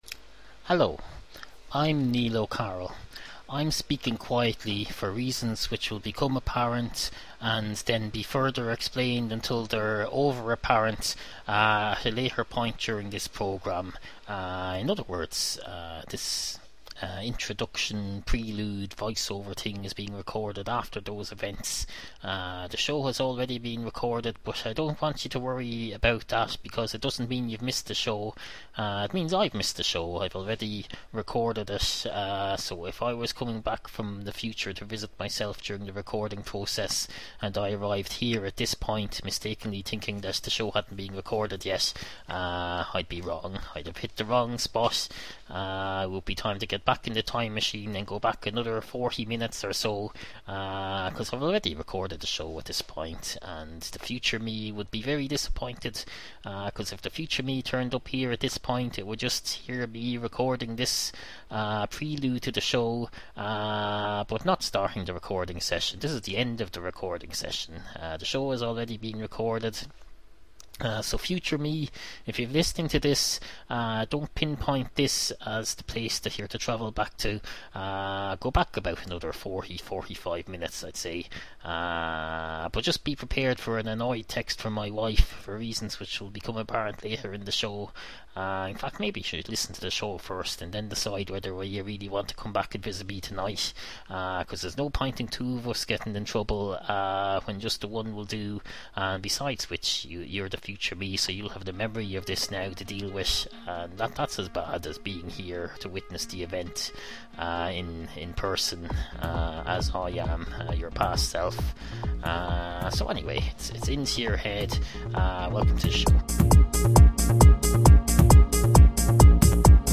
Obscure 21st Century Irish audio comedy series
Please beware: This is a vintage episode of Into Your Head, a show that evolved a lot over 18+ years.